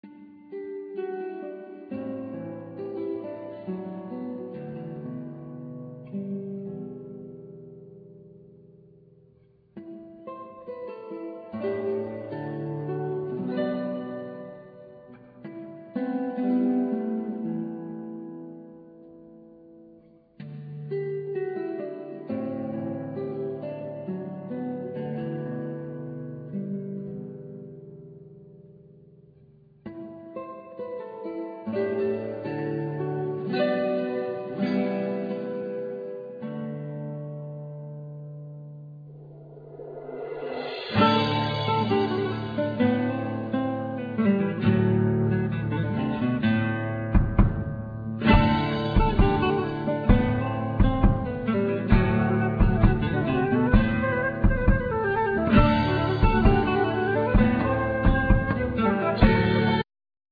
Guitar
Flute
Viola,Violin
Drums,Vibrapone,Cembalo,Piano